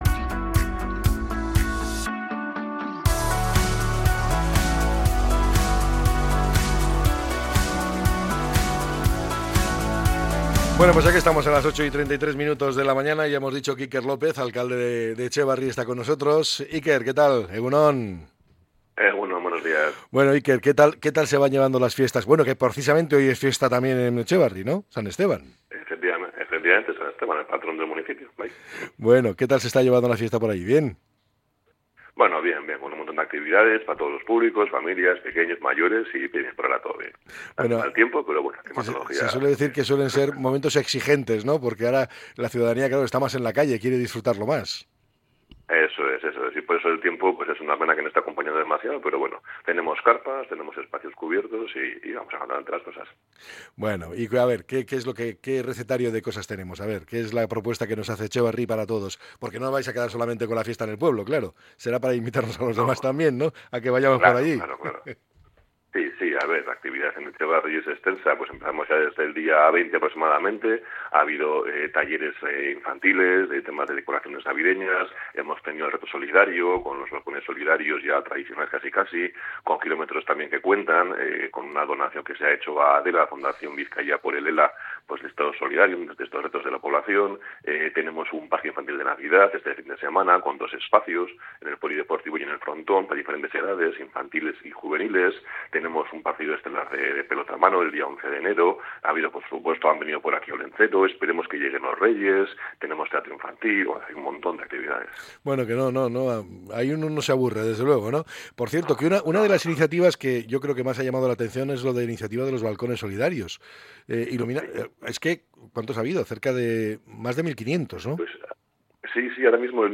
El alcalde de Etxebarri, Iker López Comerón, ha pasado por los micrófonos de EgunOn Bizkaia de Radio Popular-Herri Irratia para repasar la actualidad de la localidad en un día festivo como es San Esteban, patrón del municipio.